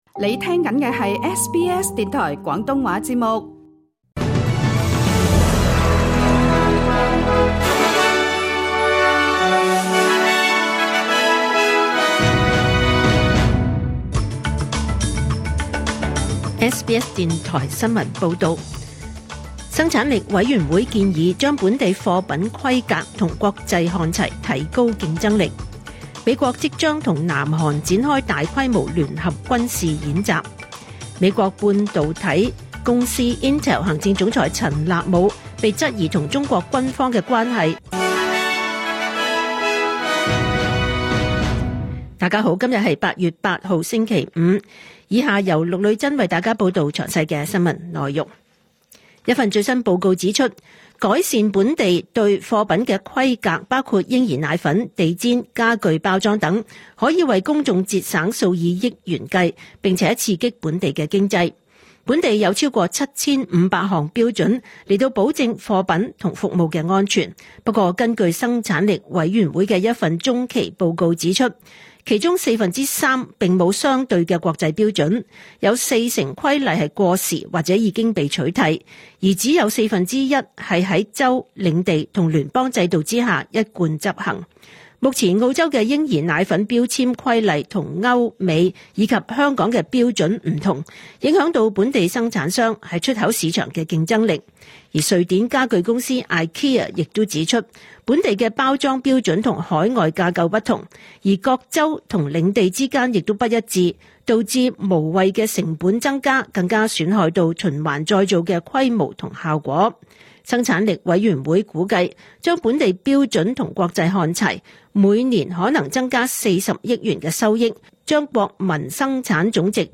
2025 年 8 月 8 日 SBS 廣東話節目詳盡早晨新聞報道。